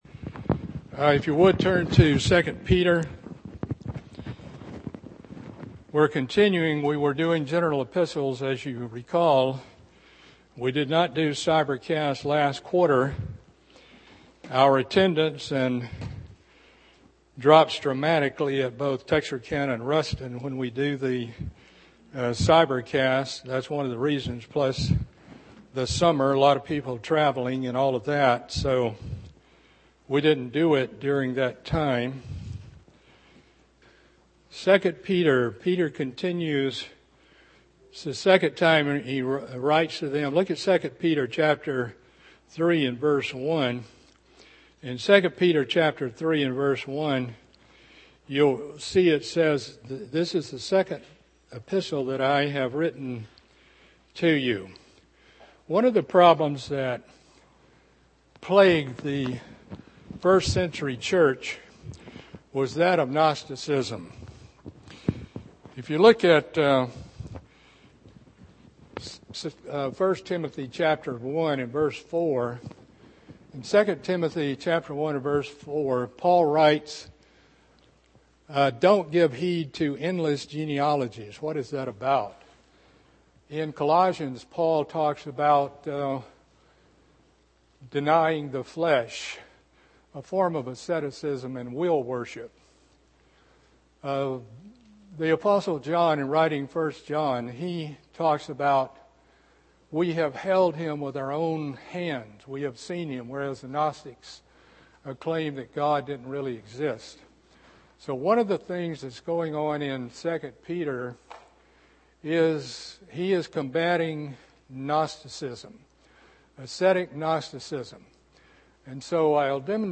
Continuing Bible study in the epistle of 2 Peter.